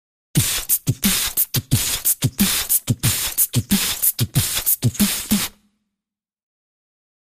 Human Beat Box, R&B Rhythm, Type 2 - Low